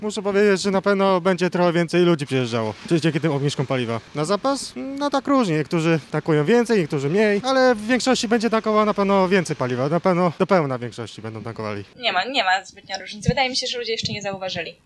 Kierowcy z regionu o obniżonych cenach paliwa [SONDA]
O sytuację zapytaliśmy pracowników stacji z naszego regionu.
REGION-PALIWA-SPRZEDAWCY.mp3